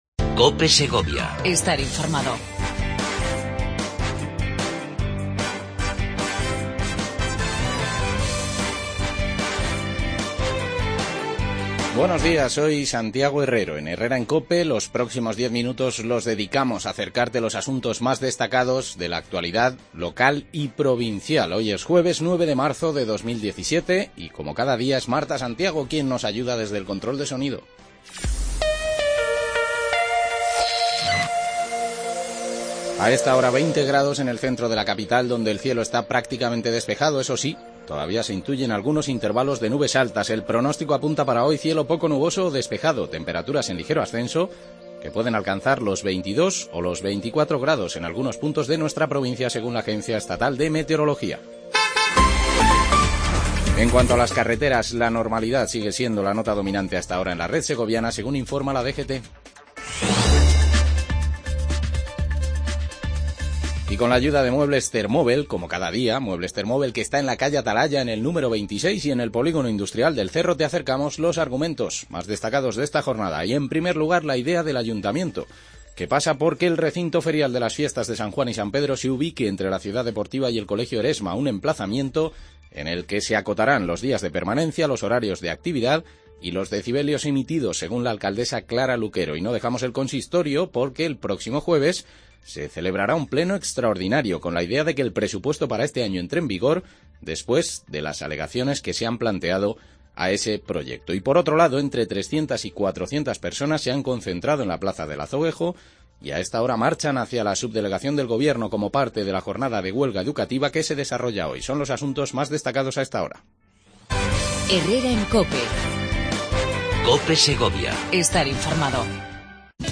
Avance de las noticias más destacadas del día. Entrevista